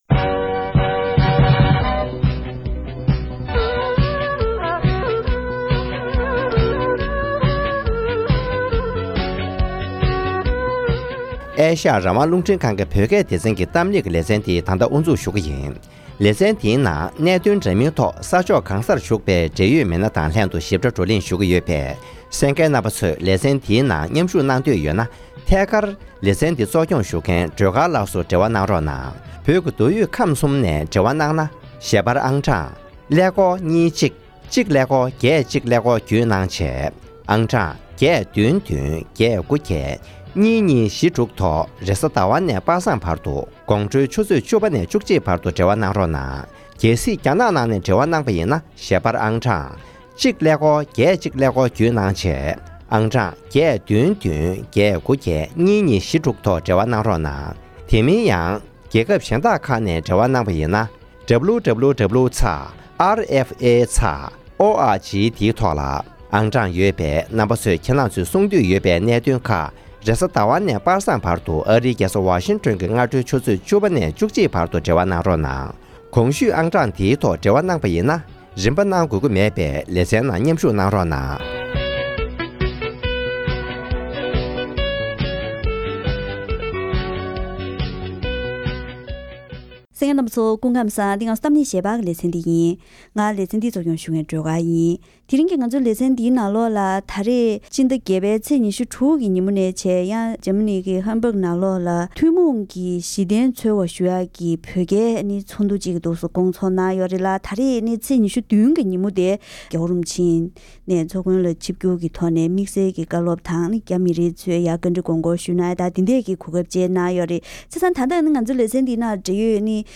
ཇར་མན་ནང་སྐོང་ཚོགས་གནང་བའི་ཐུན་མོང་གྱི་གཞི་རྟེན་འཚོལ་བ་ཞེས་པའི་བོད་རྒྱའི་ཚོགས་འདུར་༸གོང་ས་མཆོག་ནས་བཀའ་སློབ་གནང་བ།